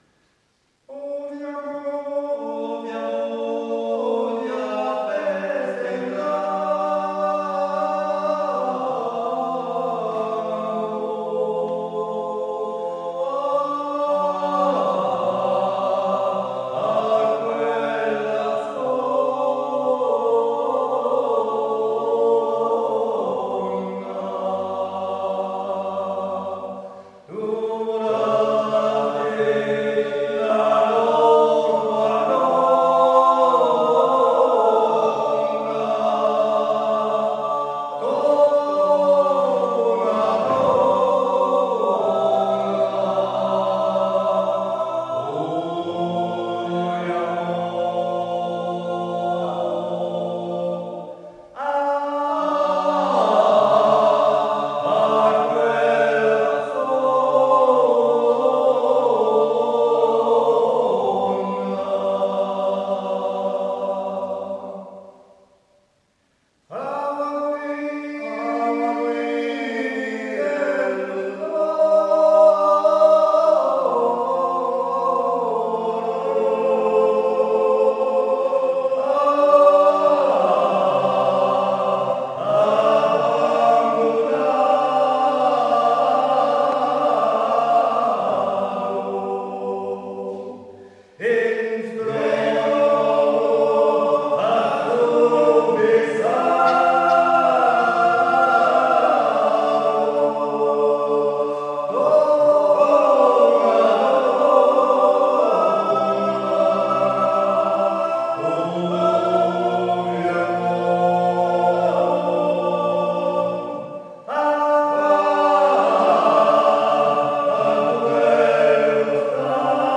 Concert à l'église Saint Pierre de Quint Fonsegrive - 16 juin 2019 - Cantu di Luna